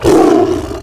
CosmicRageSounds / wav / general / combat / creatures / tiger / she / hurt3.wav
hurt3.wav